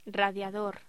Locución: Radiador
voz
locución
Sonidos: Voz humana